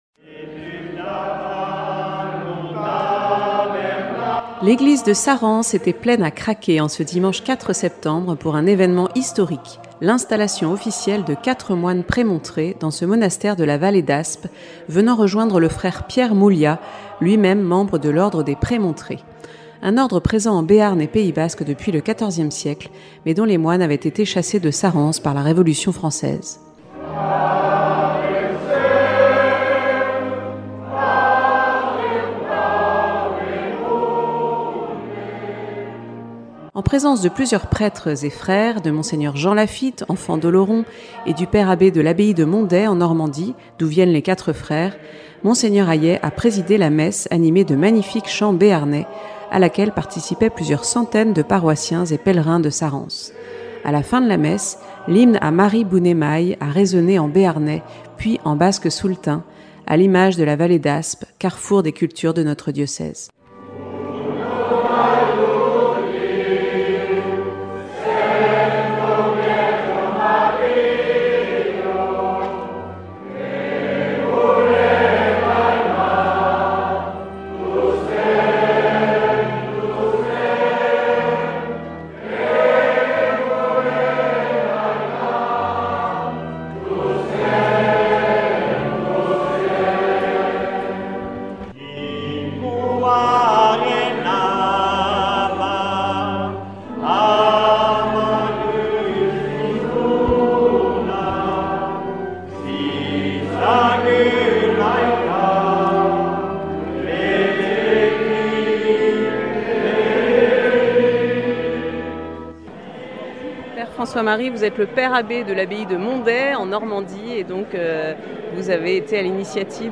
L'installation de la communauté par Mgr Aillet a eu lieu à l'occasion du traditionnel pèlerinage à Notre-Dame de Sarrance, le dimanche 4 septembre 2022 (...)